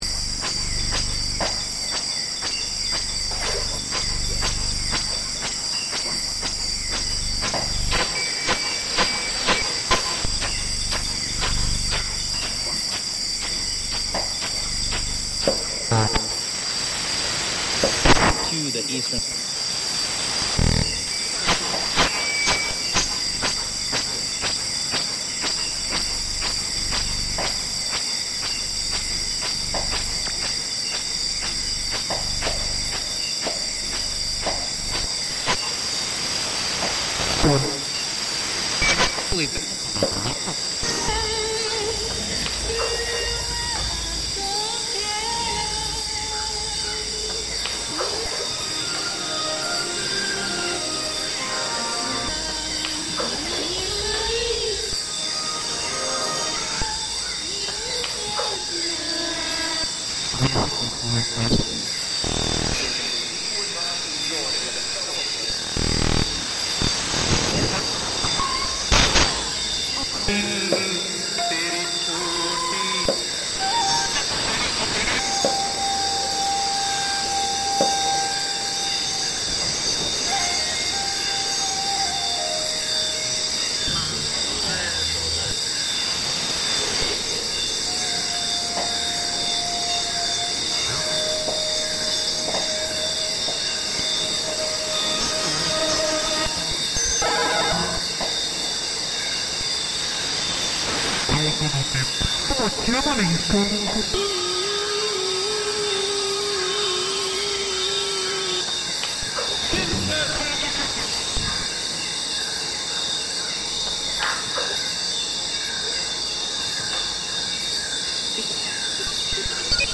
Borneo rainforest recording reimagined